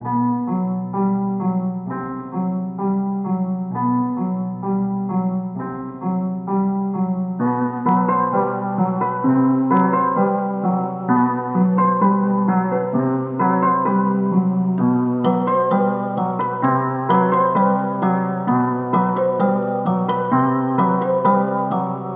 130bpm